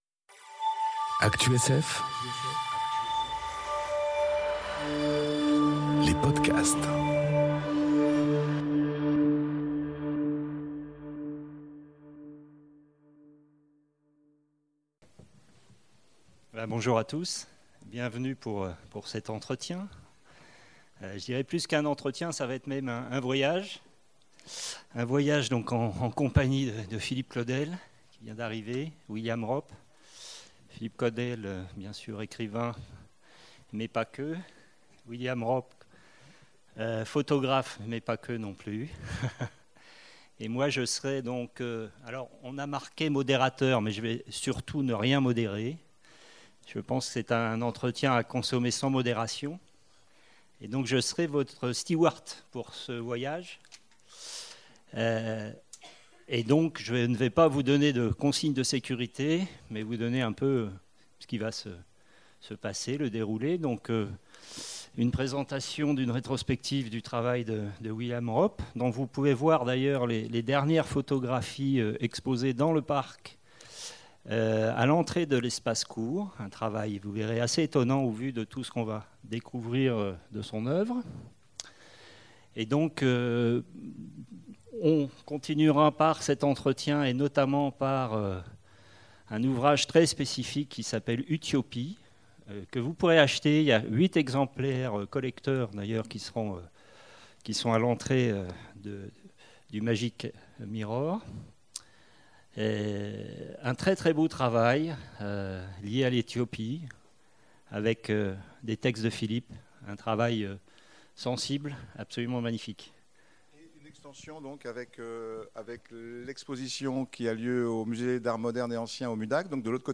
Modération